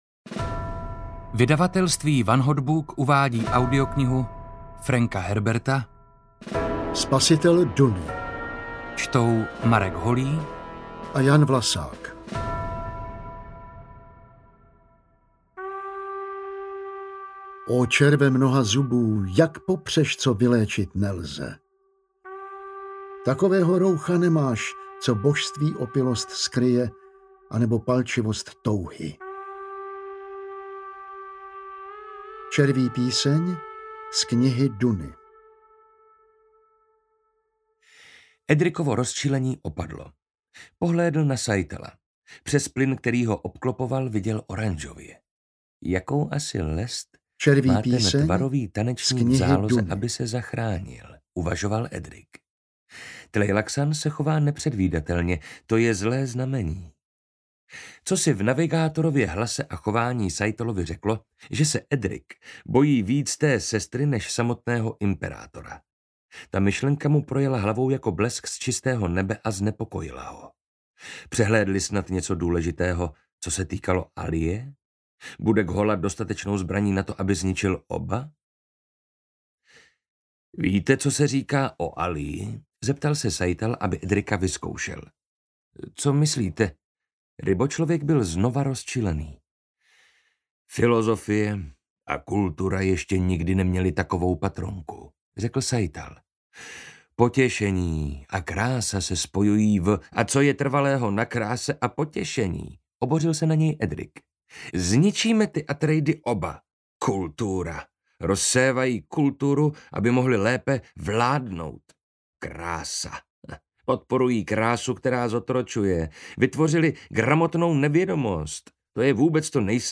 Spasitel Duny audiokniha
Ukázka z knihy
• InterpretMarek Holý, Jan Vlasák